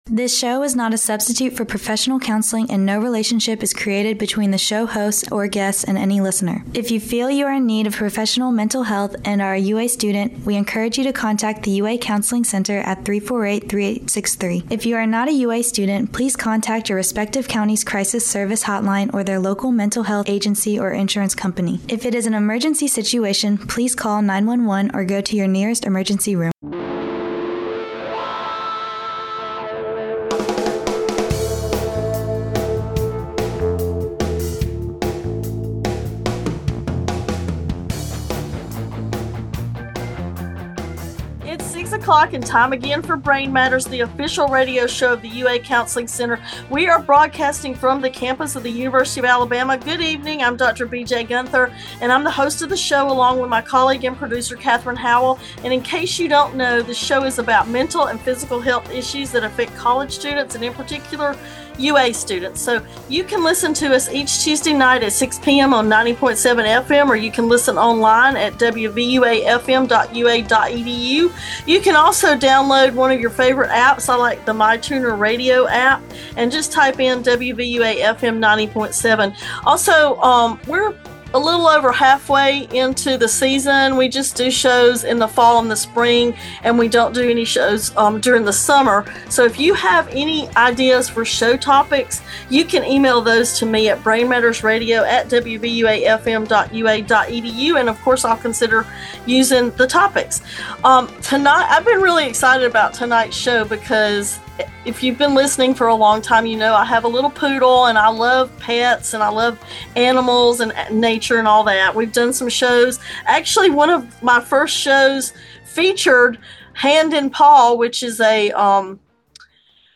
interviews veterinarian